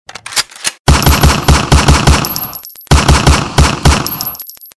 Gun.mp3